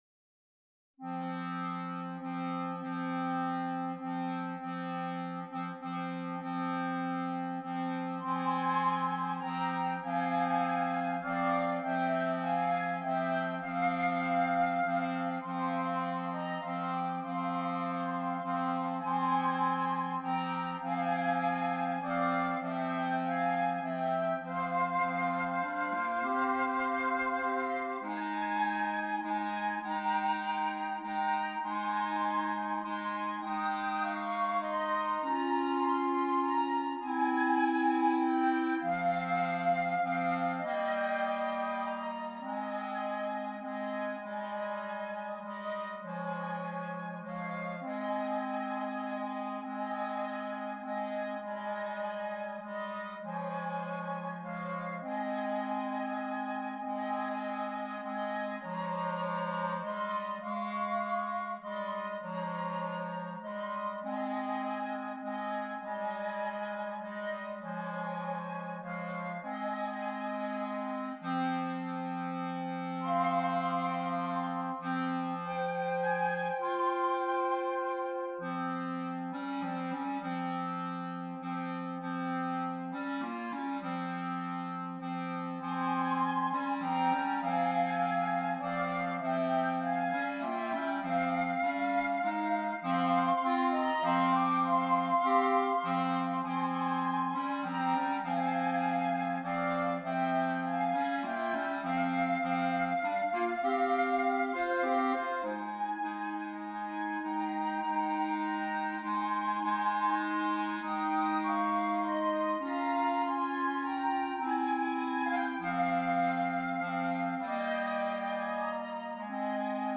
2 Flutes, 2 Clarinets
Traditional